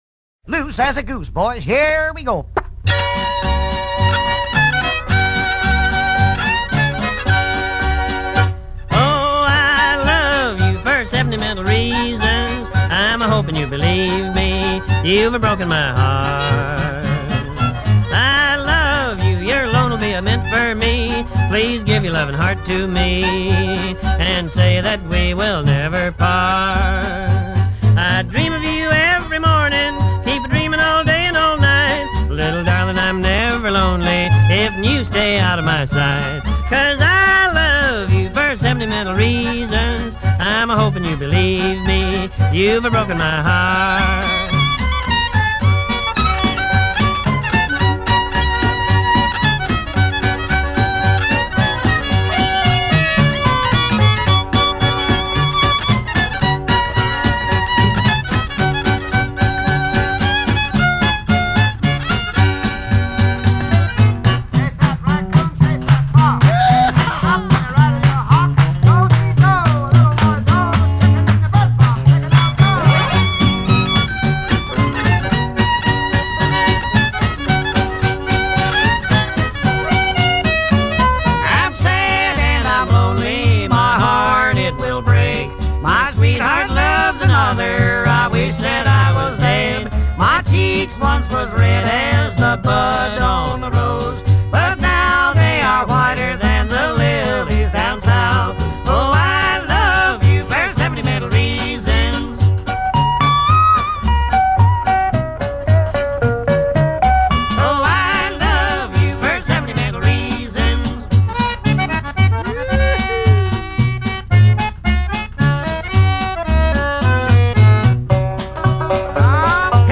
comedy records